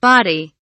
body kelimesinin anlamı, resimli anlatımı ve sesli okunuşu
body.mp3